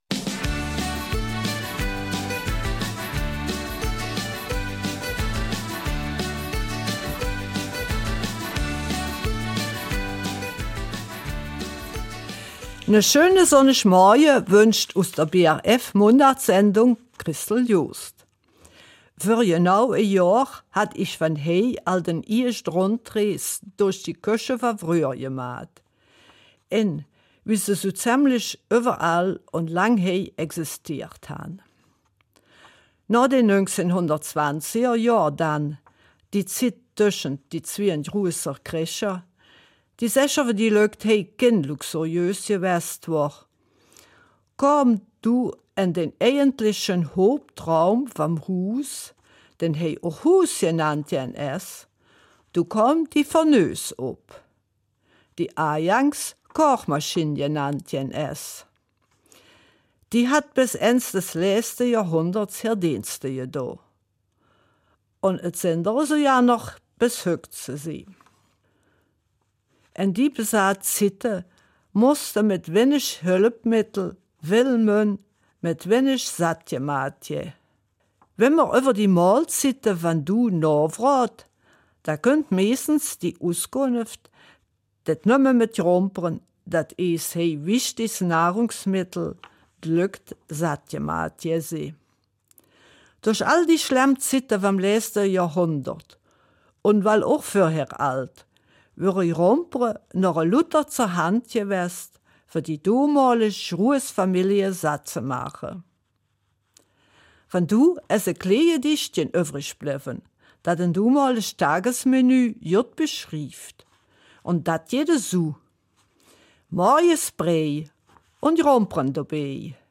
In der Mundartsendung vom 13. April geht es um die Fortsetzung der Rundreise durch eine Eifeler Küche, wie sie bis weit über die Hälfte des 20. Jahrhundert existiert hat.